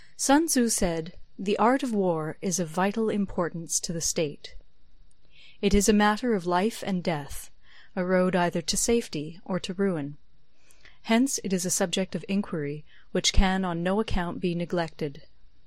voice.mp3